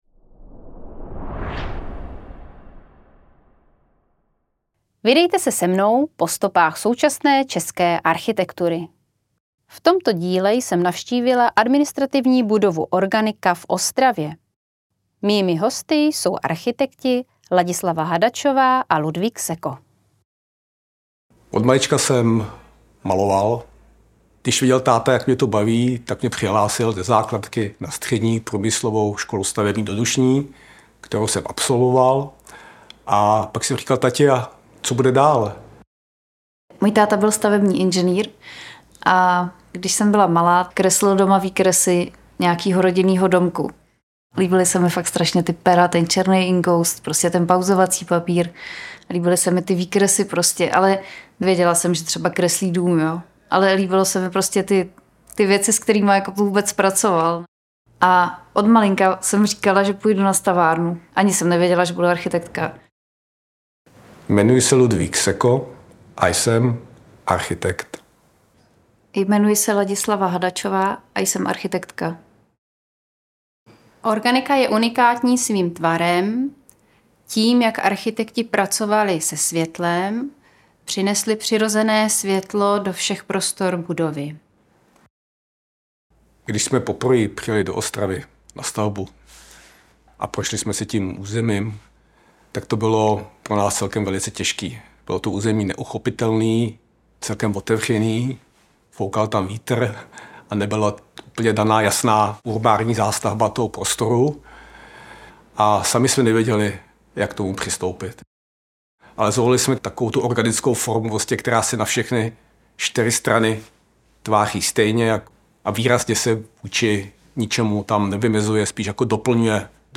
reportáže z realizovaných staveb a diskuze přímo nad projekty, nikoliv ve studiu